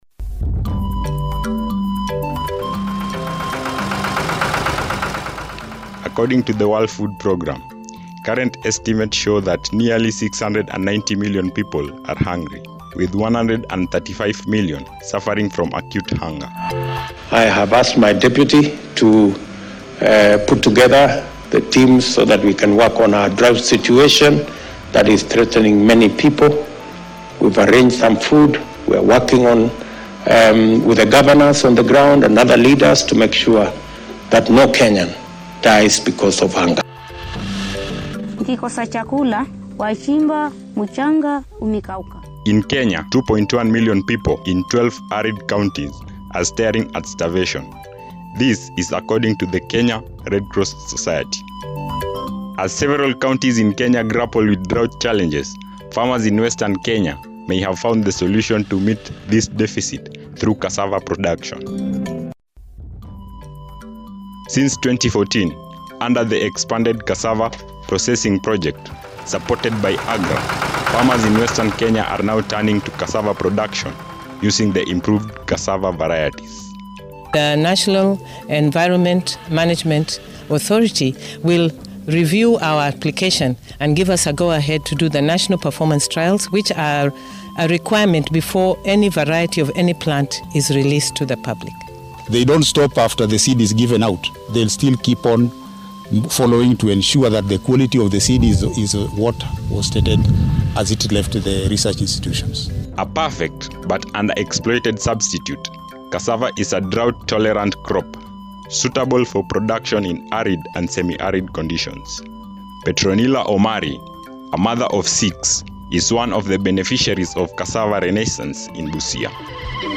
RADIO-DOCUMENTARY-MITIGATING-HUNGER-.mp3